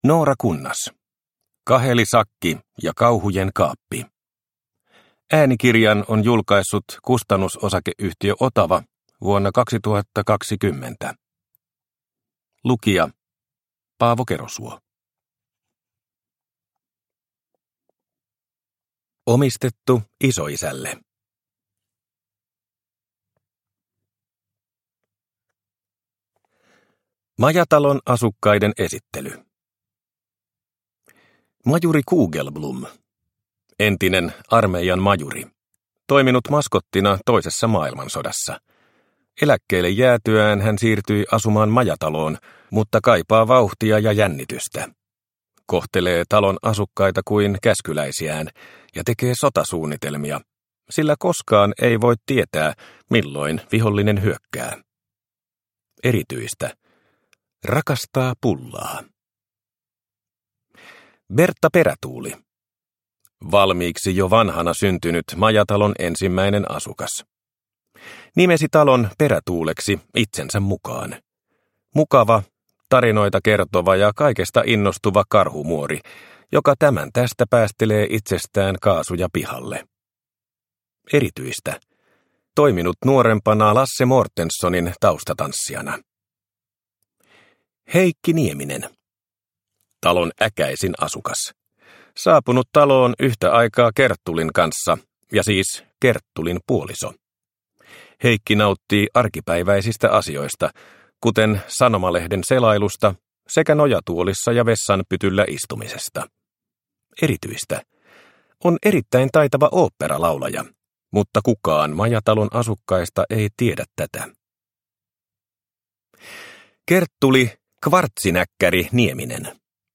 Kaheli sakki ja kauhujen kaappi – Ljudbok – Laddas ner